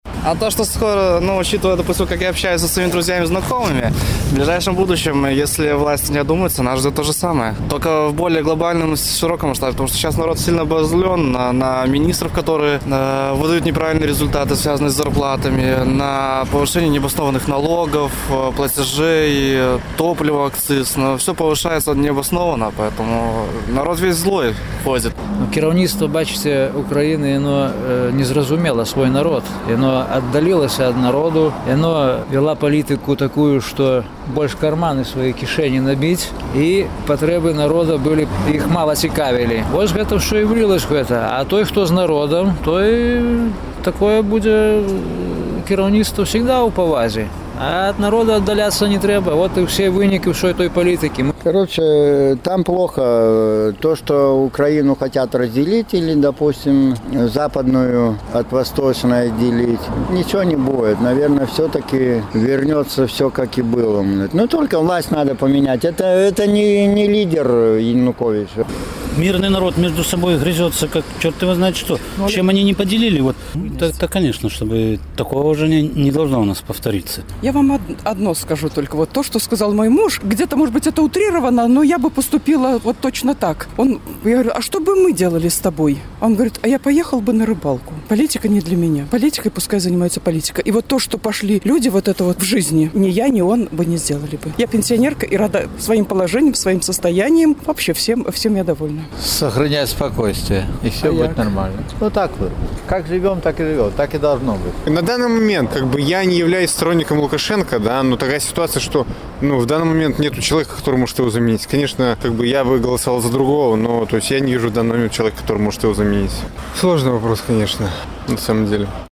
Якія высновы варта зрабіць беларусам, назіраючы за вынікамі палітычнага супрацьстаяньня ва Ўкраіне? З такім пытаньнем наш карэспандэнт зьвяртаўся да гарадзенцаў.